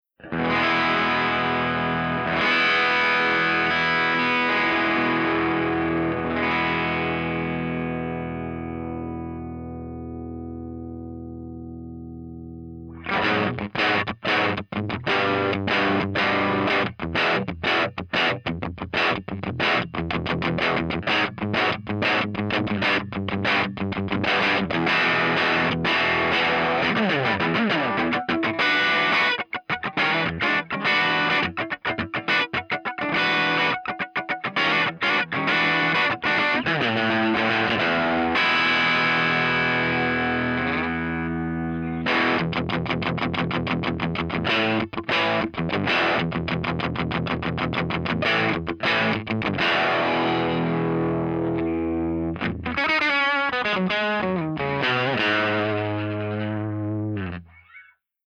062_FENDERTWIN_DRIVE_SC
062_FENDERTWIN_DRIVE_SC.mp3